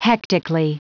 Prononciation du mot : hectically
hectically.wav